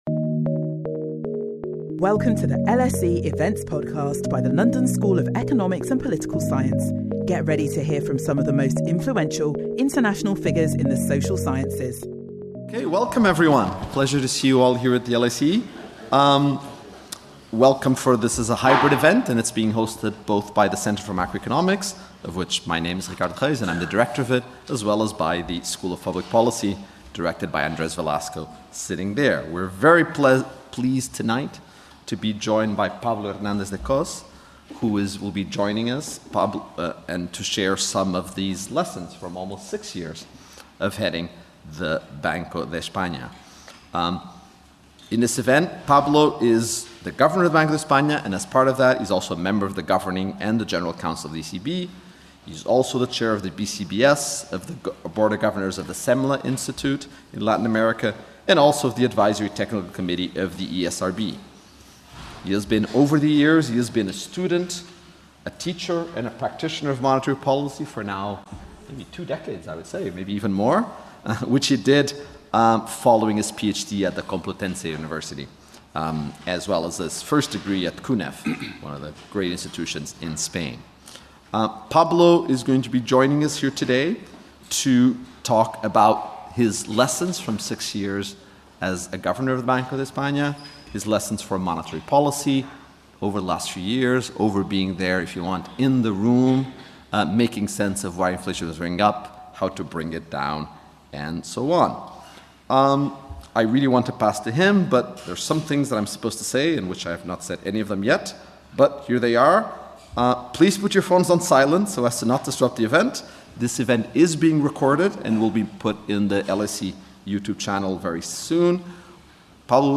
Governor Hernández de Cos addresses the lessons learned so far from the latest inflationary-disinflationary episode in the euro area and the European Central Bank’s response to it.